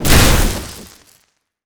electric_lightning_blast_02.wav